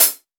Closed Hats
Dilla Hat 33.wav